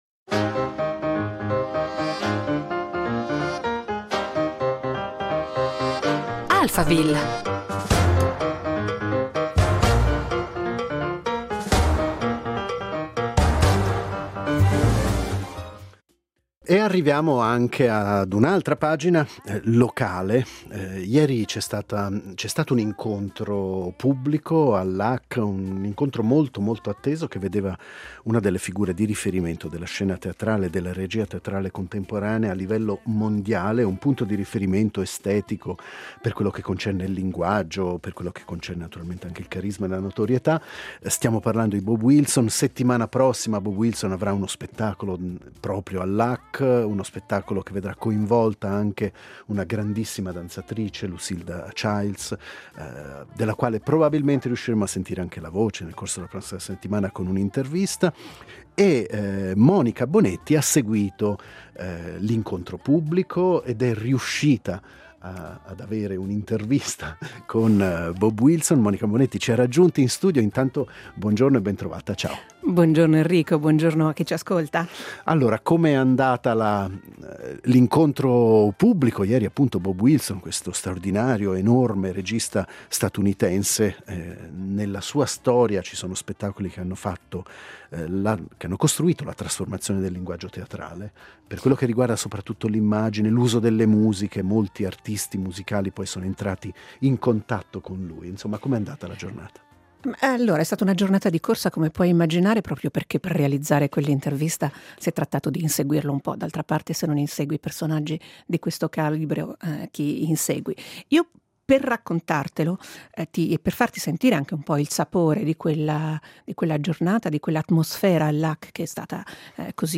Intervista a Bob Wilson